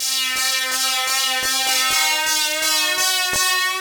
HP Riff_126_C.wav